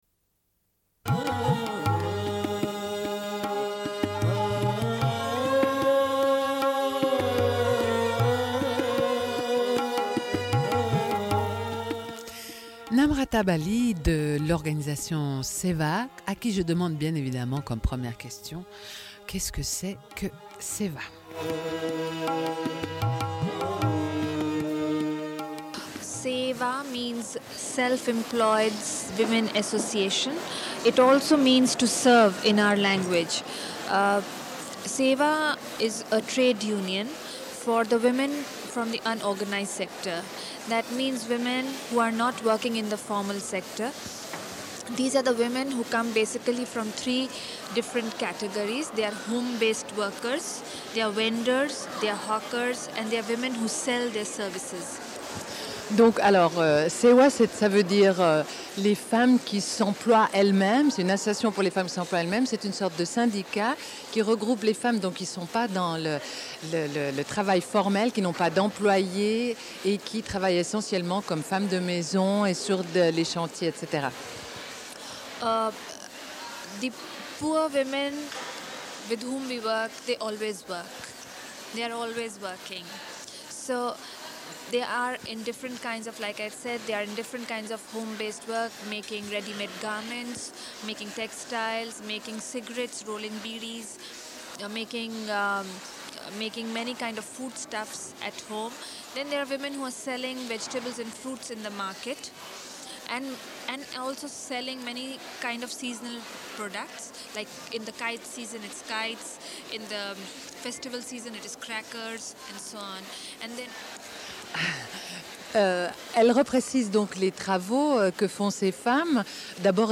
Suite de l'émission : diffusion d'un entretien avec Gurinder Chadha, réalisatrice britanico-indienne, réalisé à l'occasion du Festival de films de femmes de Créteil.